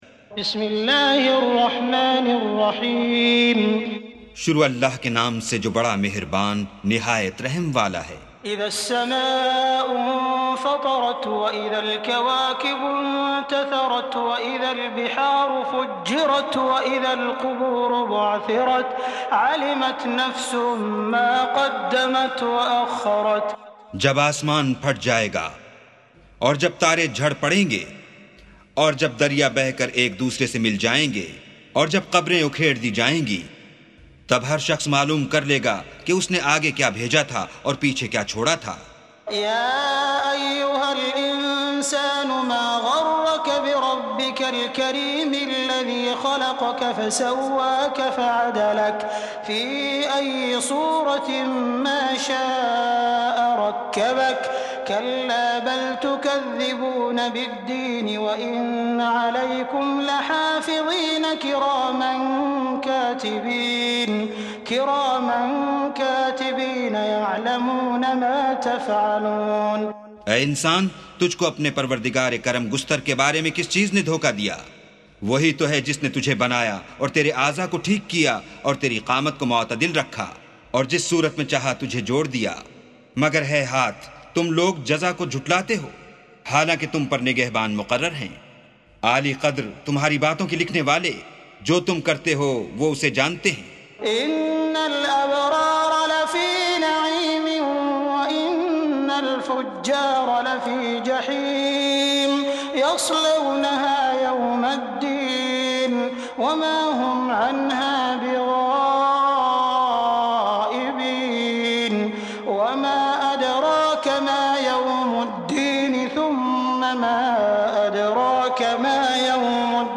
سُورَةُ الانفِطَارِ بصوت الشيخ السديس والشريم مترجم إلى الاردو